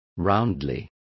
Complete with pronunciation of the translation of roundly.